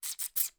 • Hamster Calls
To add to the player’s interest in controlling the hamster, I recorded a series of hamster calls using a human voice and set them to play randomly when the hamster hit the wall in each room.
Hamster_Hiss_3-1.wav